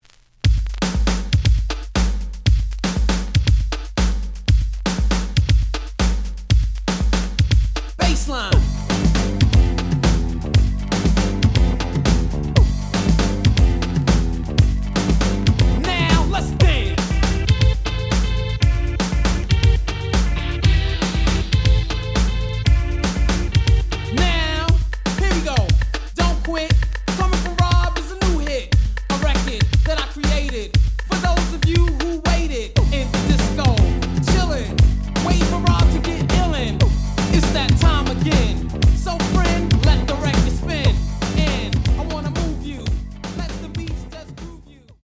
The audio sample is recorded from the actual item.
●Genre: Hip Hop / R&B
Slight noise on beginning of A side, but almost good.)